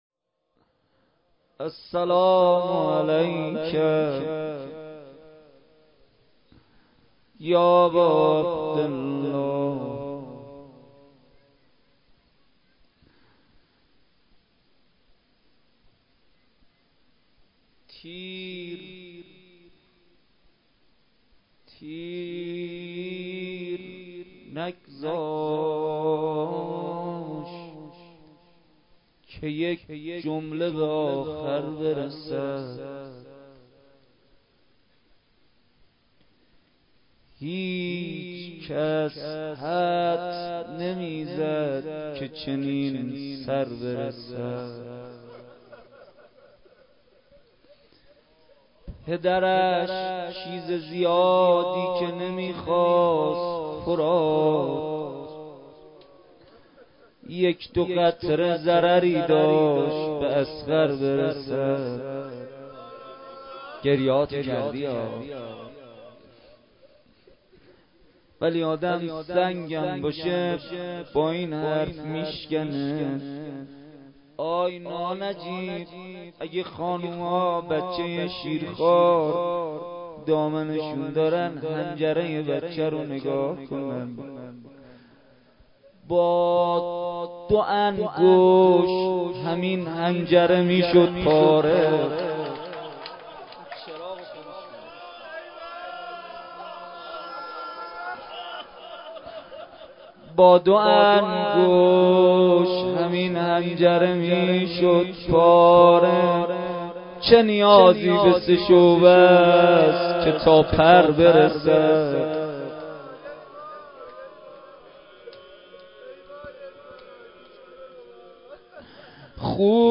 شب نهم ماه رمضان با مداحی کربلایی محمدحسین پویانفر در ولنجک – بلوار دانشجو – کهف الشهداء برگزار گردید.
بخش اول:دعا و مناجات بخش دوم:روضه لینک کپی شد گزارش خطا پسندها 0 اشتراک گذاری فیسبوک سروش واتس‌اپ لینکدین توییتر تلگرام اشتراک گذاری فیسبوک سروش واتس‌اپ لینکدین توییتر تلگرام